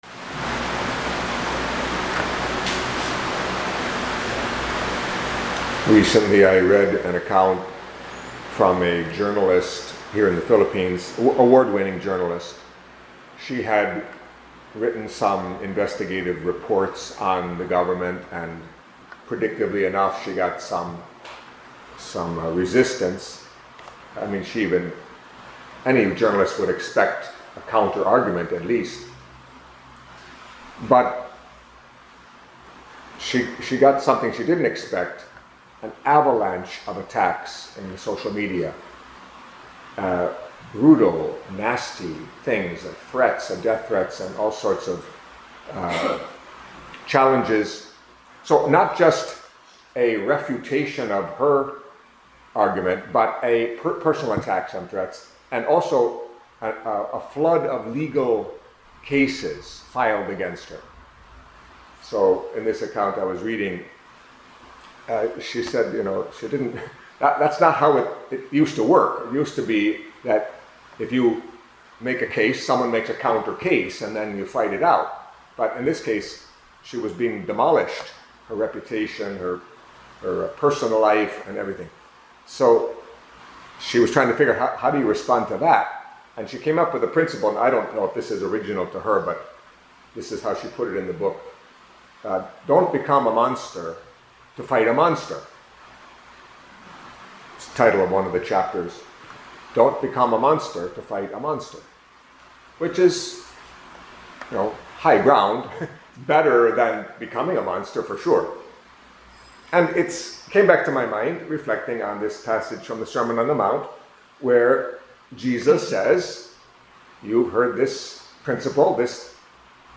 Catholic Mass homily